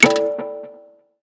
button_7.wav